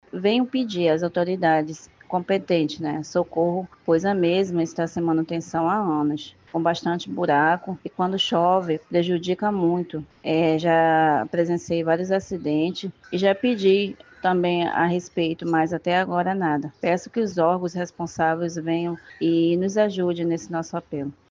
SONORA-MORADORA-COMUNIDADE.mp3